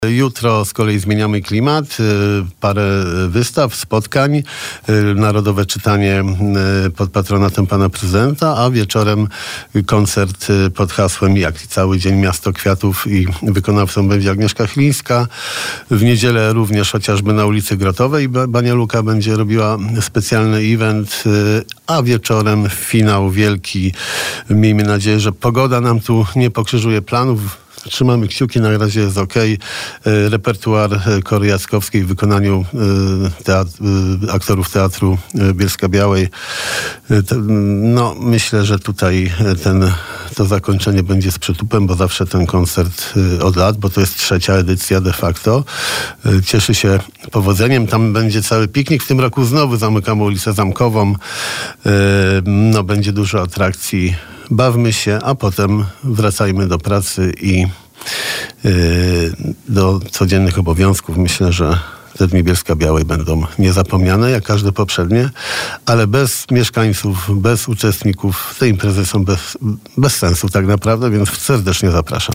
O święcie miasta rozmawialiśmy dzisiaj z prezydentem Jarosławem Klimaszewskim.